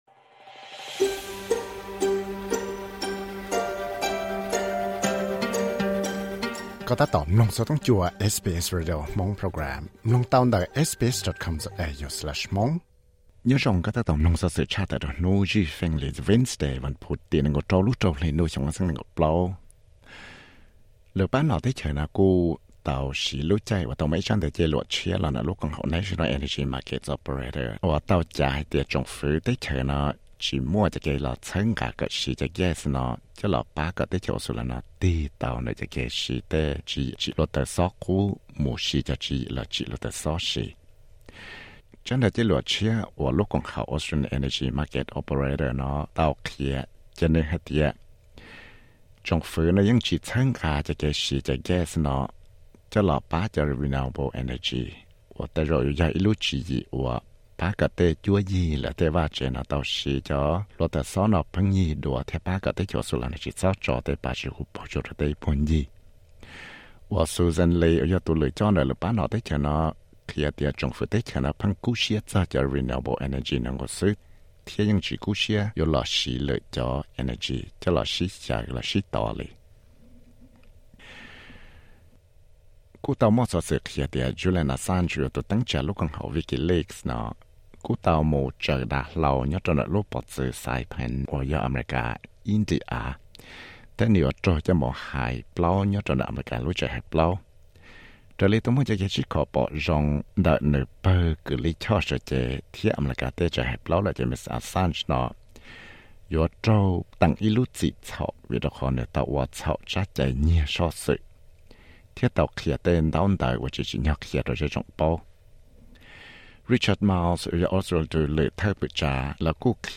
Xov xwm luv Credit: SBS/Max Banyat